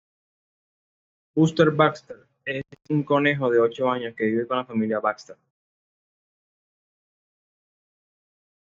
Pronounced as (IPA)
/koˈnexo/